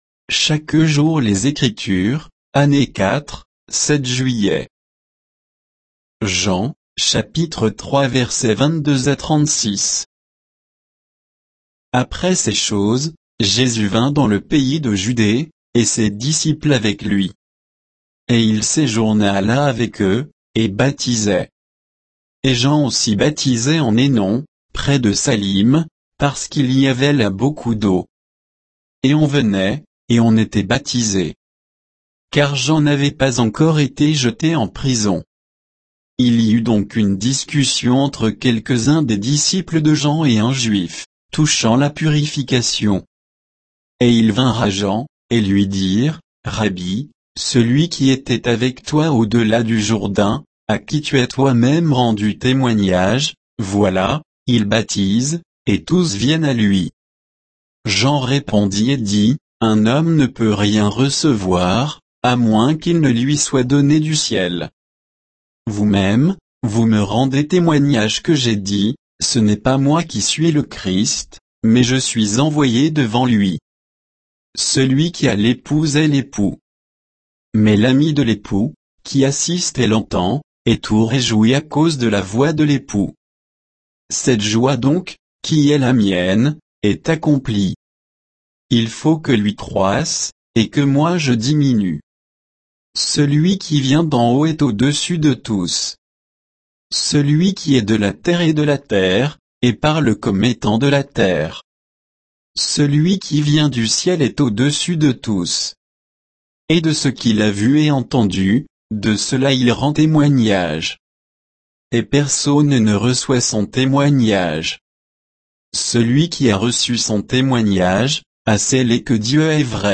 Méditation quoditienne de Chaque jour les Écritures sur Jean 3, 22 à 36